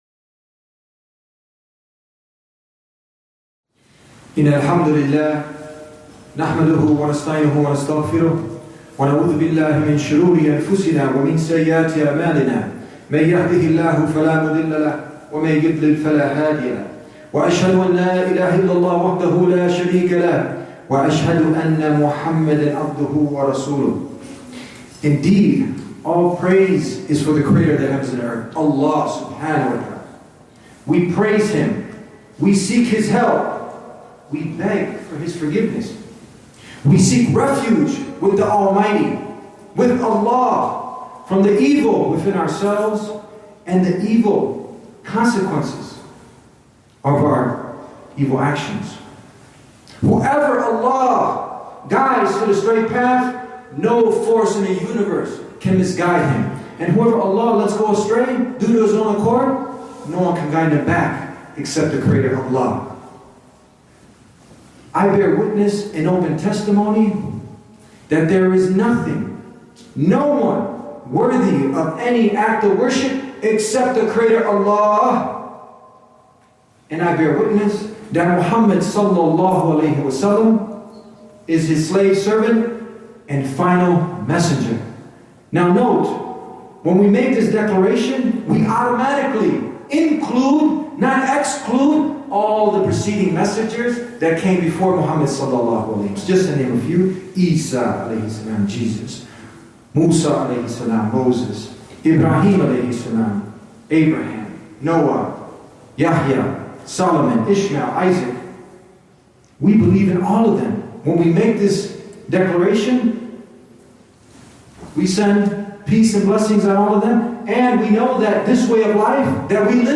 PURPOSE driven in ISLAM – Khutbah – The Deen Show